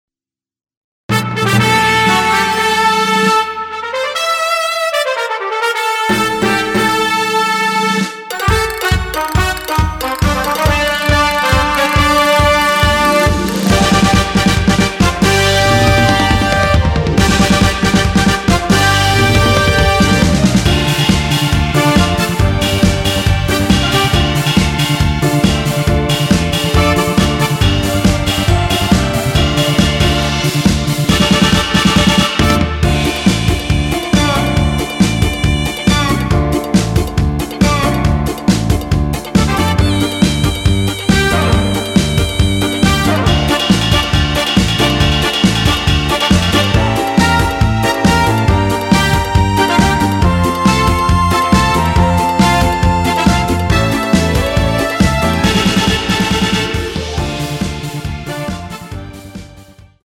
원키에서(-1)내린 MR입니다.
Dm
◈ 곡명 옆 (-1)은 반음 내림, (+1)은 반음 올림 입니다.
앞부분30초, 뒷부분30초씩 편집해서 올려 드리고 있습니다.
중간에 음이 끈어지고 다시 나오는 이유는